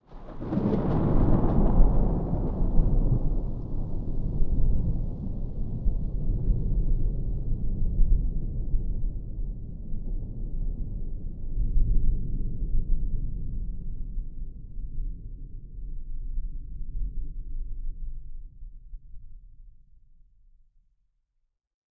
thunderfar_26.ogg